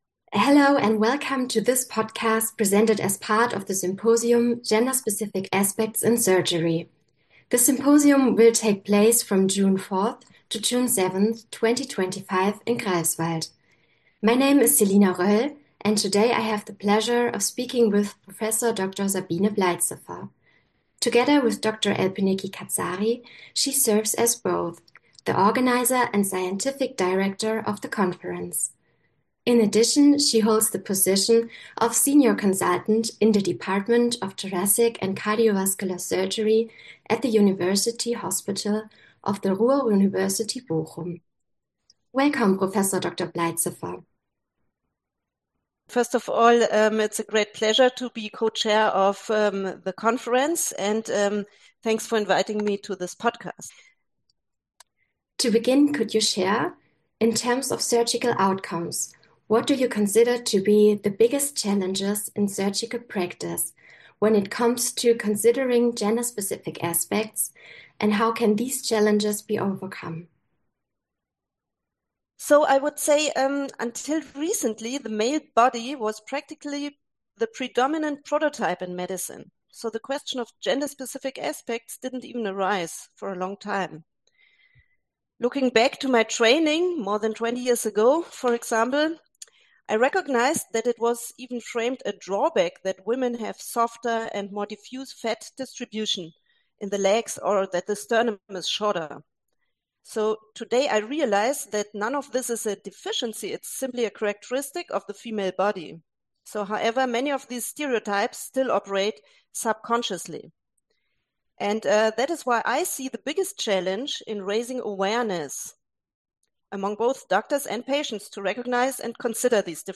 Interview-Sprache: Englisch